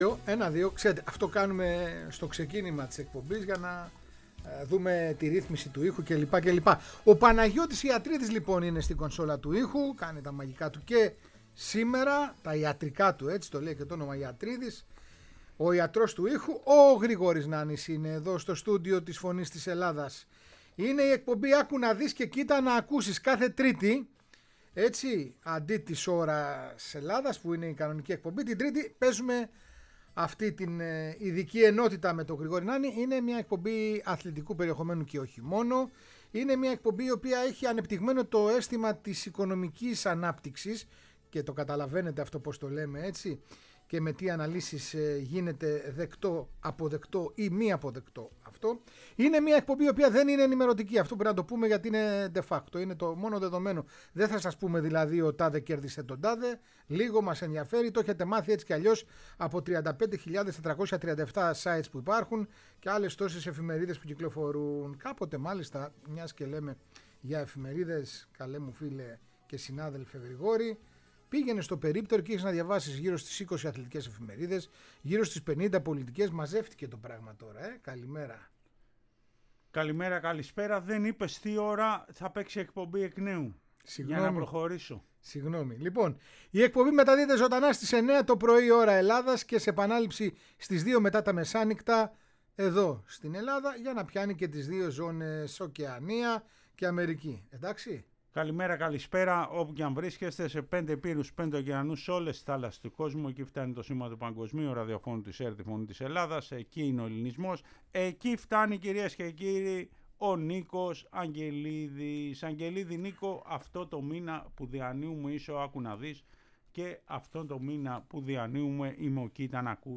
Η ανατρεπτική “αθλητική” εκπομπή